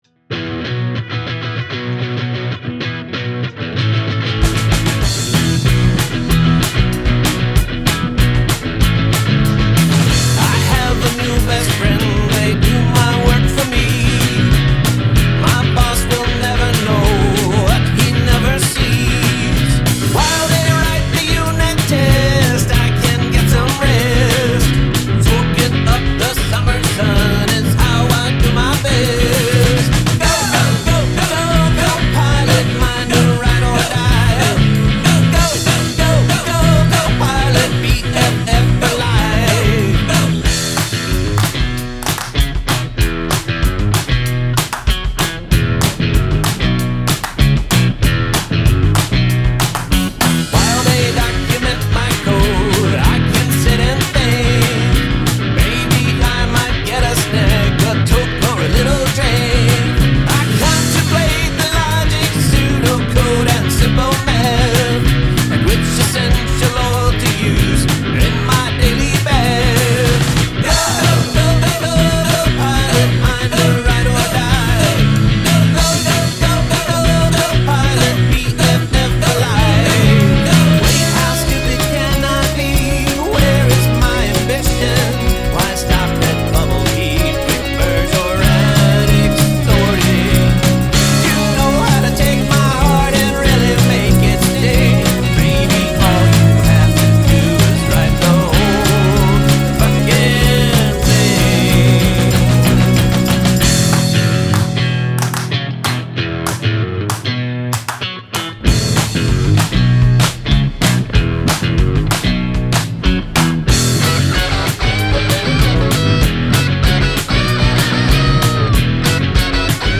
Guest Lead Vocals
The trio is tight!